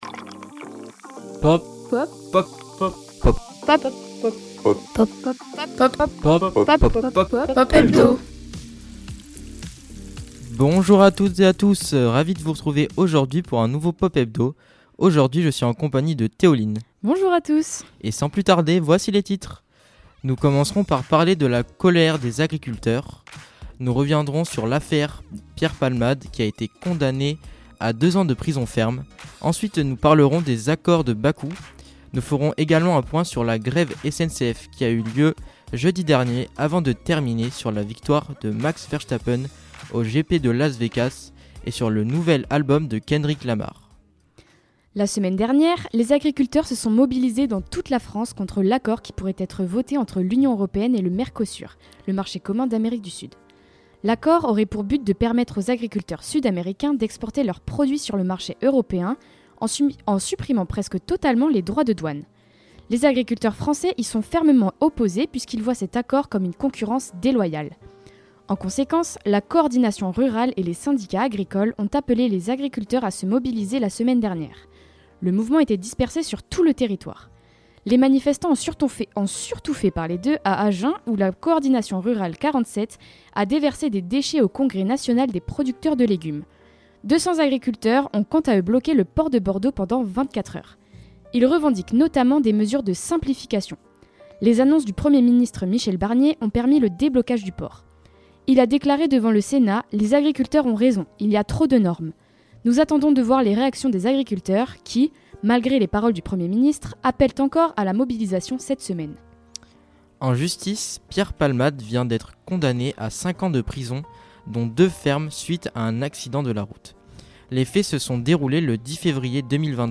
Programme à retrouver en direct tous les lundis entre 12h et 13h sur Radio Campus Bordeaux (merci à eux), et en rediffusion sur notre site Web et Spotify !